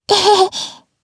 Lewsia_A-Vox_Happy2_jp.wav